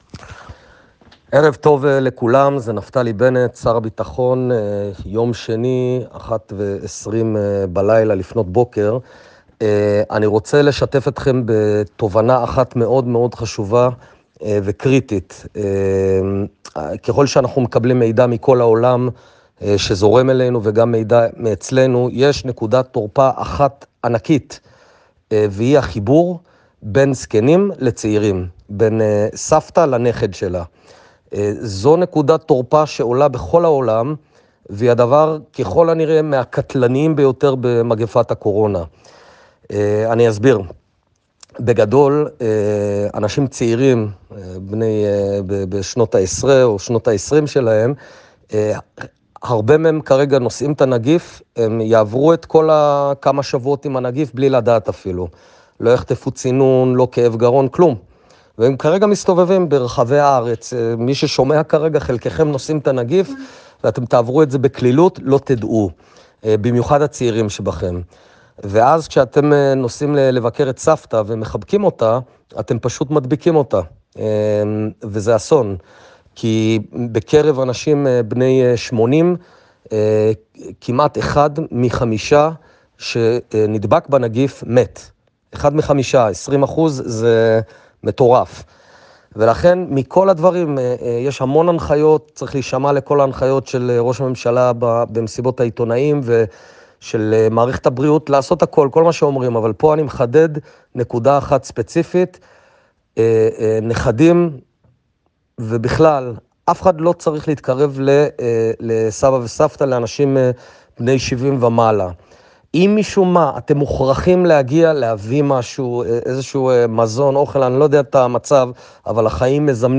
שר הביטחון, נפתלי בנט, הוציא במהלך הלילה (שלישי) הודעה קולית לאזרחי ישראל בעניין התפשטות נגיף הקורונה, בה הוא קרא לא להתקרב אל האוכלוסייה המבוגרת במדינה בכדי לשמור על חייהם.